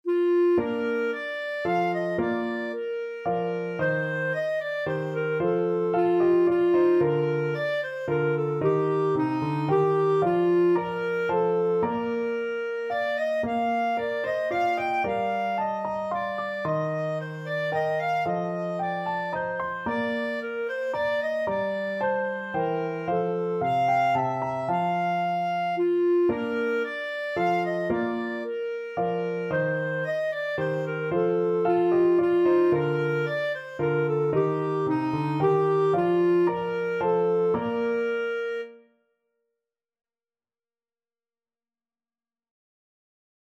Clarinet
3/4 (View more 3/4 Music)
Moderately Fast ( = c. 112)
Classical (View more Classical Clarinet Music)